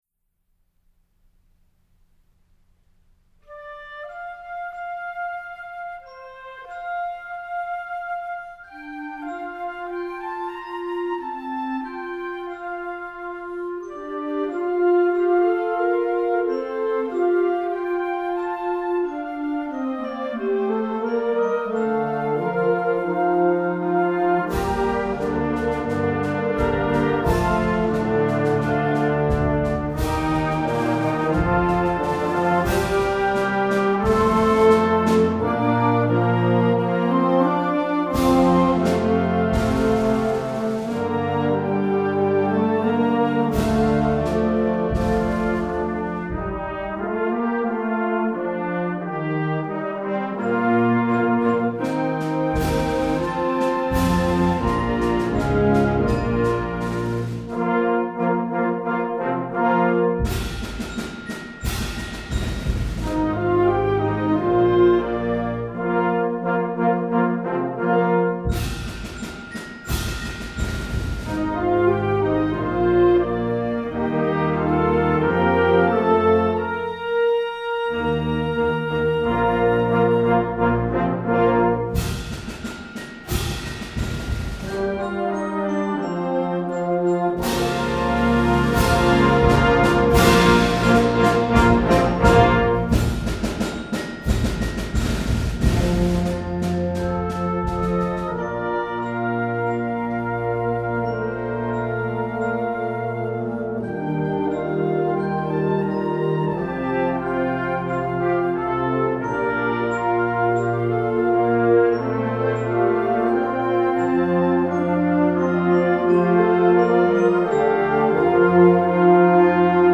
Genre: Band
Flute
Oboe
Bassoon
Bb Clarinet
Eb Alto Saxophone
Bb Trumpet 1
F Horn
Trombone
Tuba
Bells
Snare Drum